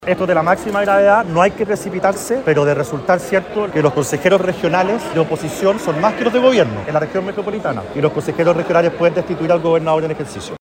Por su parte, el diputado y presidente de la UDI, Guillermo Ramírez, recordó que -en el caso del Gobierno de Santiago- los consejeros regionales de oposición son más que los oficialistas, por lo que, perfectamente, en caso de comprobarse estas acusaciones, podrían solicitar la destitución de Claudio Orrego.